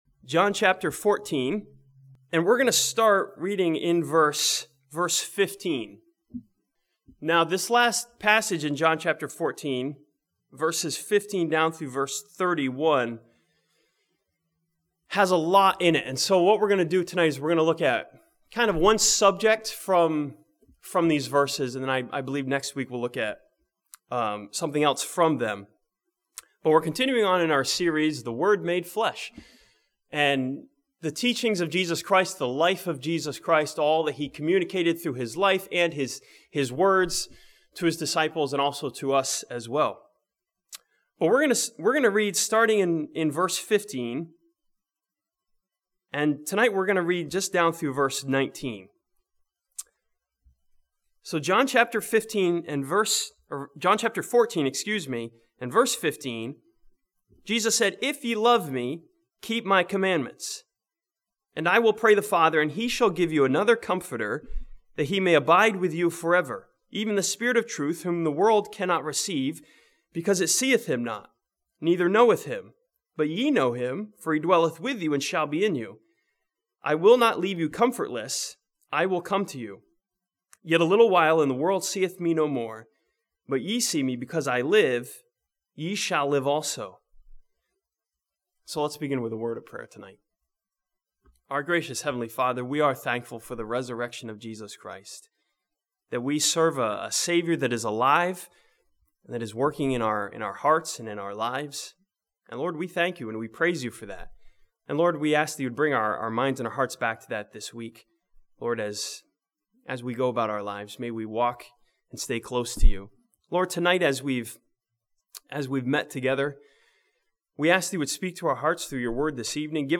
This sermon from John chapter 14 reassures the believer with the fact that you're in good hands with the indwelling Holy Spirit.